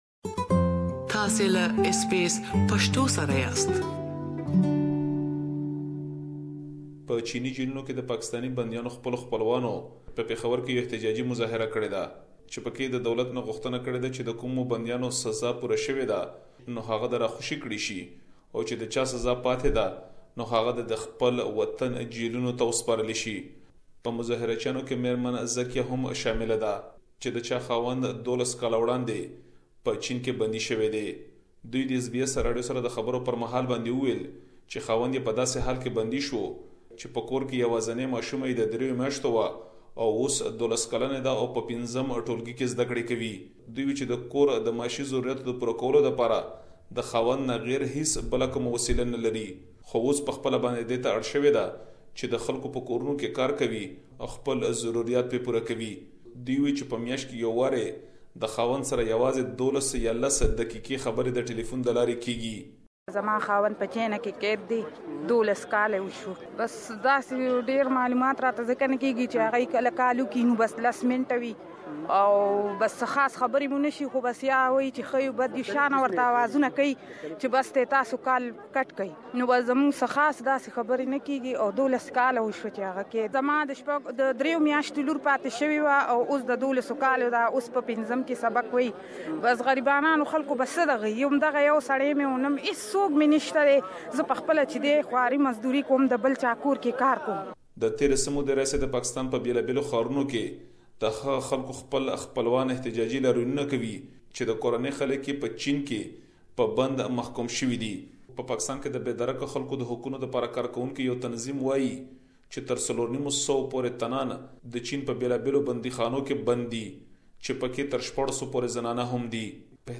SBS Pashto